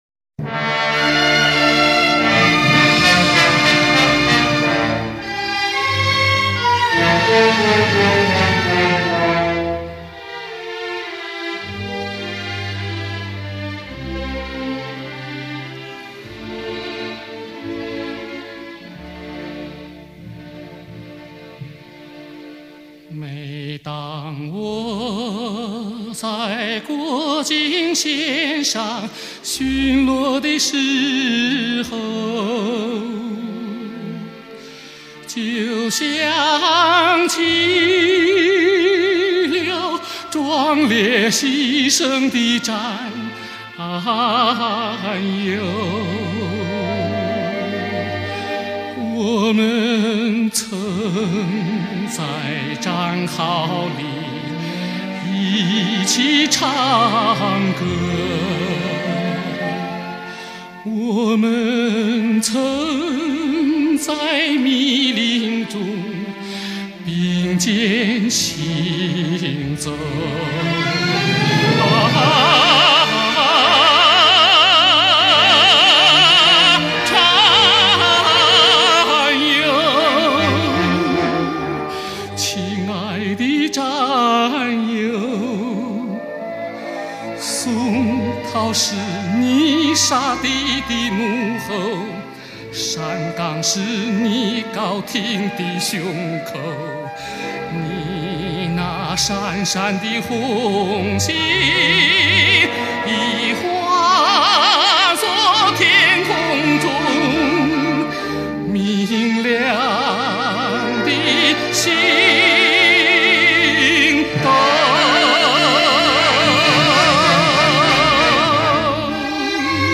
演唱热情奔放，富有乐感，吐字清晰，声音流畅，高音稳定、透明，辉煌而华丽。